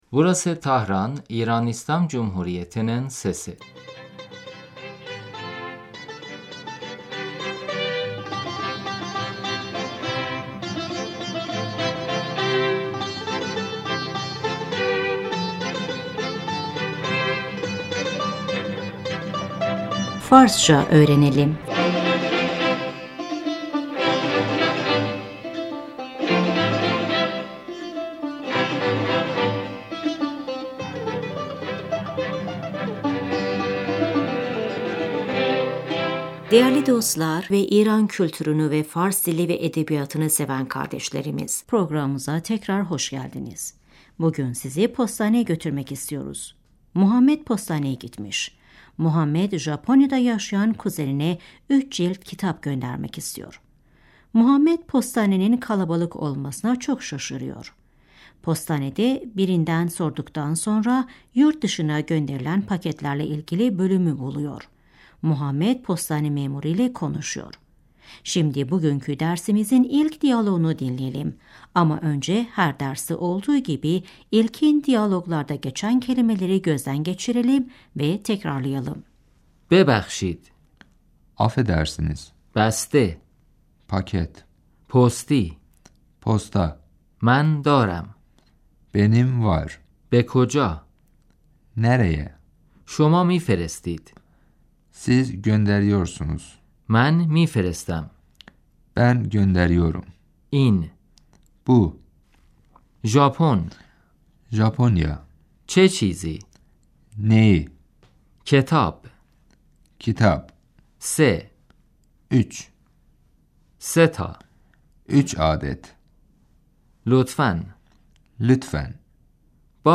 Şimdi Muhammed ile postane memuru arasında geçen diyaloğu dinleyin ve tekrarlayın. صدای همهمه آرام مردم - در اداره پست Kalabalığın gürültüsü, postanede محمد - ببخشید آقا .